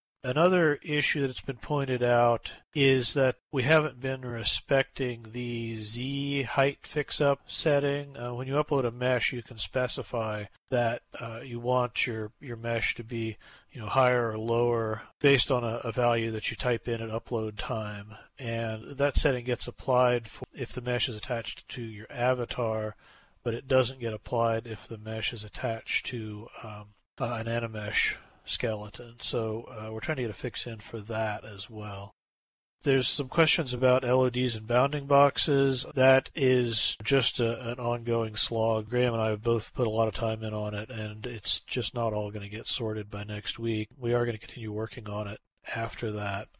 The following notes are taken from the Content Creation User Group (CCUG) meeting, held on  Thursday, June 14th, 2018 at 13:00 SLT.